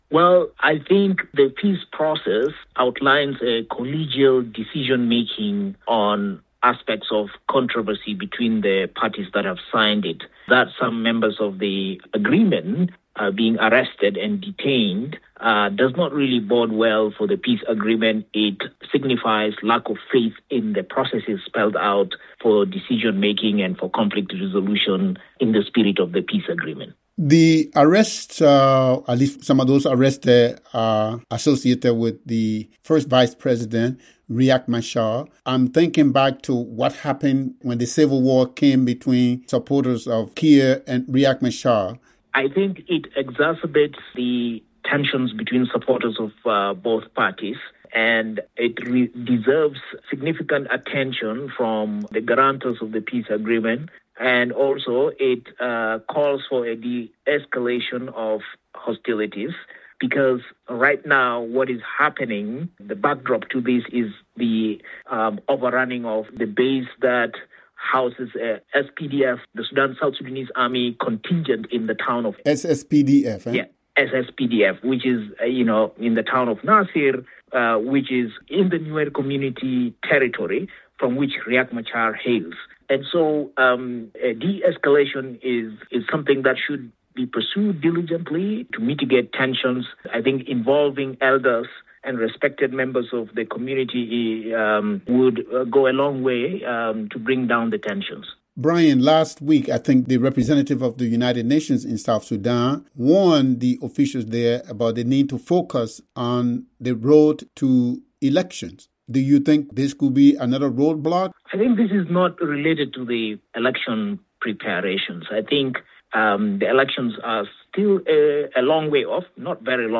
Analyst: South Sudan arrests signal no confidence in peace process